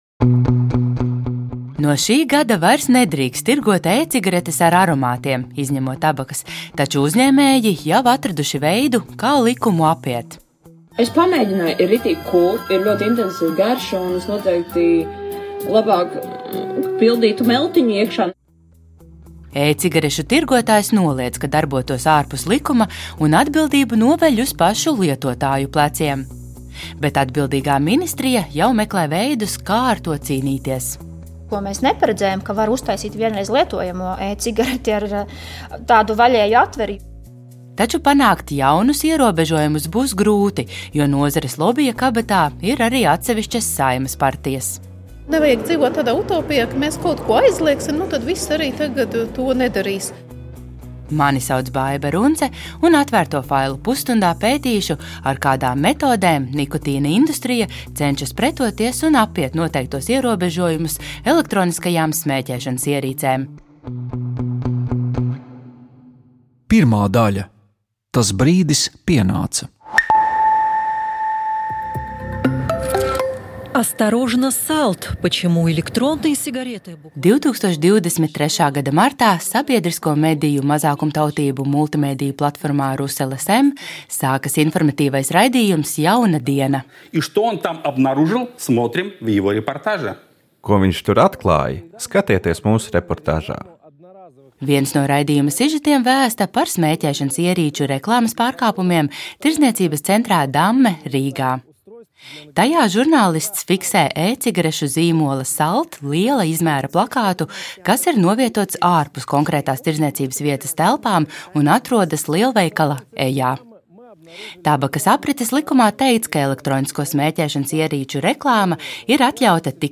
No politikas līdz biznesam, par sociālo dzīvi un krimināldrāmām – pētnieciskais raidījums “Atvērtie faili” iedziļinās mūsu laika svarīgākajos notikumos. Katrs raidījums ir dokumentāls audiostāsts par procesiem un cilvēkiem, kas veido mūsu sabiedrisko dzīvi.